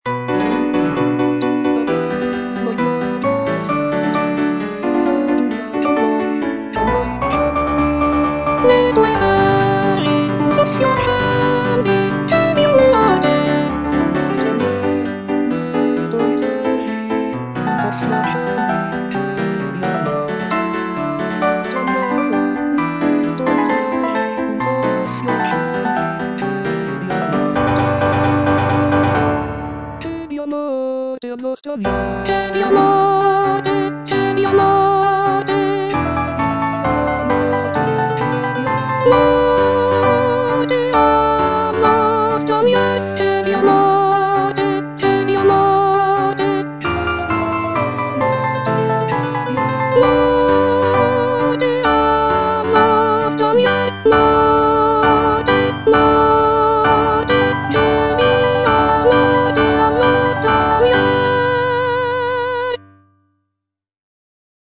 G. VERDI - DI LIETO GIORNO UN SOLE FROM "NABUCCO" Ne' tuoi servi un soffio accendi (soprano II) (Voice with metronome) Ads stop: auto-stop